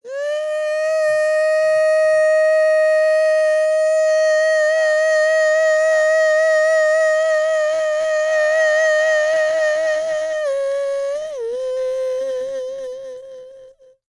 E-CROON 3039.wav